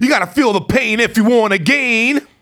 RAPHRASE14.wav